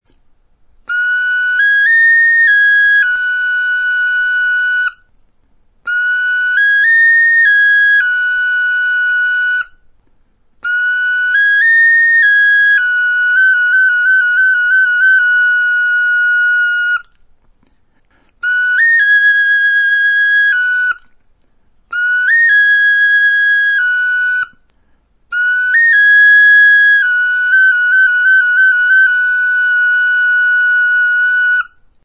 FLUTE; BONE FLUTE
Aerophones -> Flutes -> Fipple flutes (one-handed)
Hezurrezko flautatxo zuzena da.